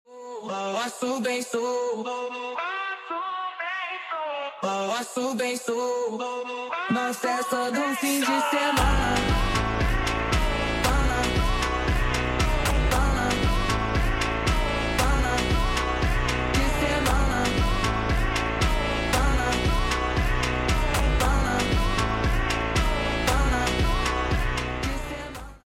tiktok sound effects funny